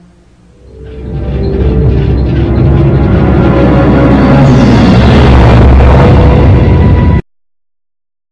Studio B - Foley Room
Also in the Foley Room, was a small metal table.
The unmistakable sound of the Imagination Station wind-down sequence (when a person is exiting the station)